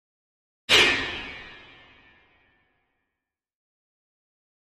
Piano Jarring Piano Hit, High Tones - Single